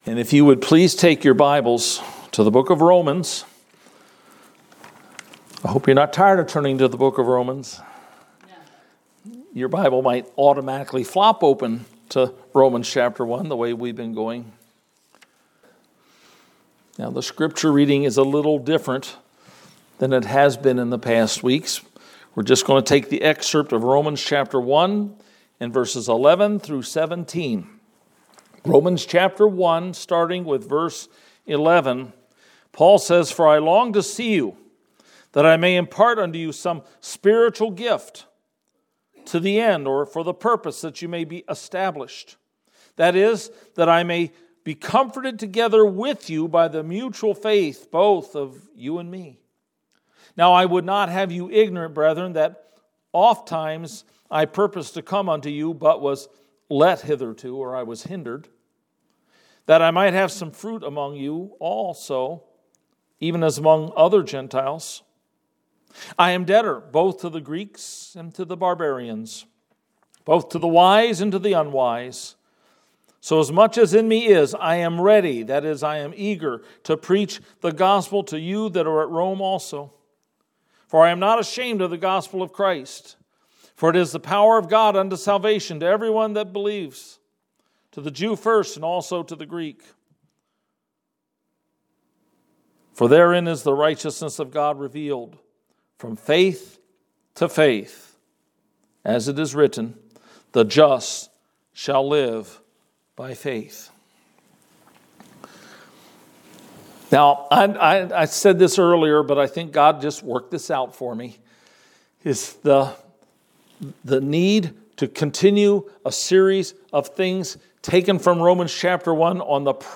We are having a special focus, this Sunday, because of the serious and timely need to consider and pray for our nation.